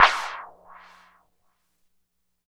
88 MD CLAP-R.wav